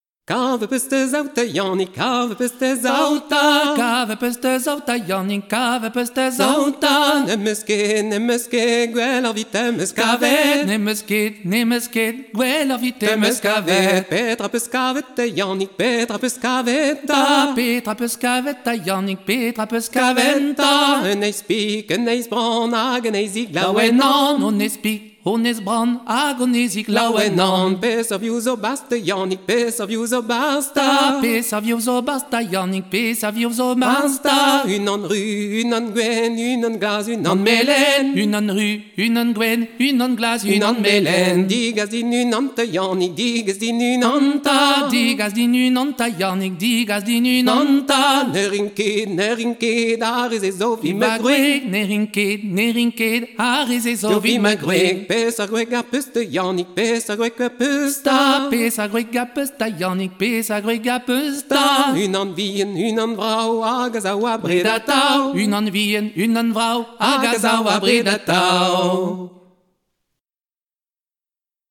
Gavotte Aven Yanning an difouper neizhioù